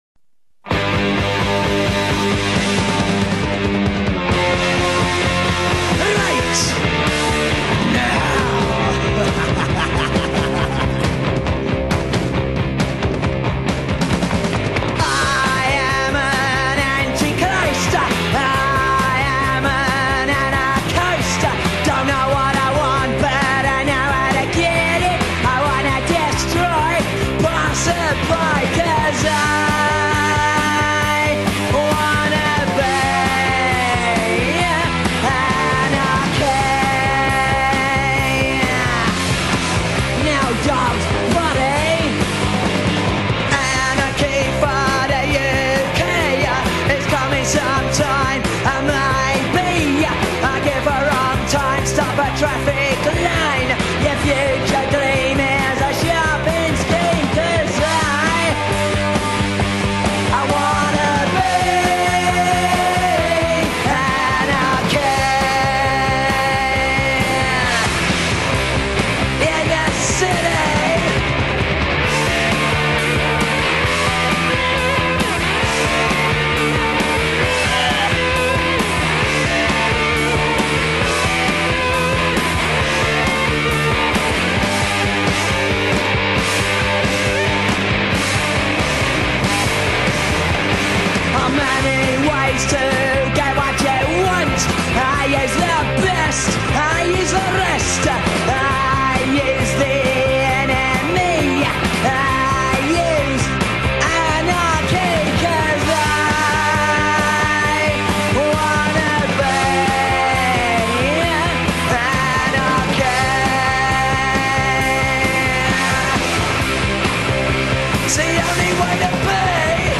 Un zeste de punk, c’est toujours pour le moral 😊